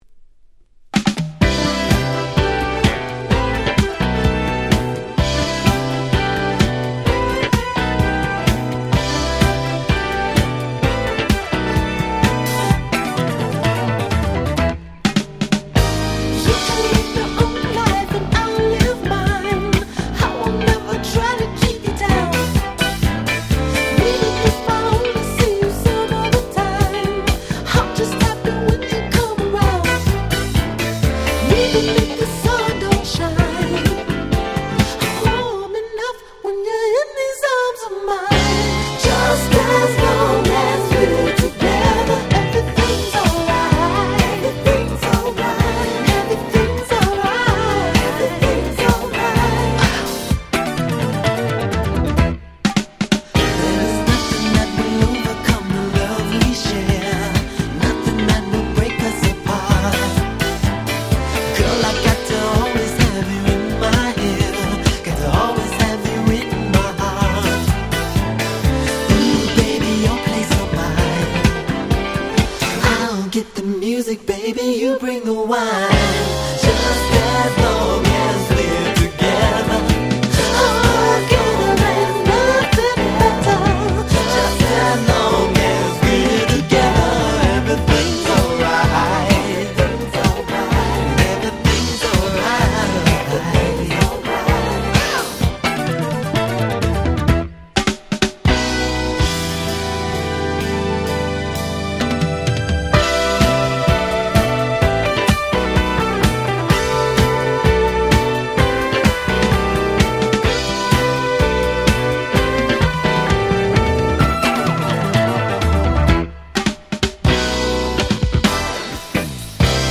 79' Super Hit Disco / Dance Classics !!